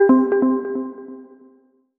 Bouncy Trance Alert.wav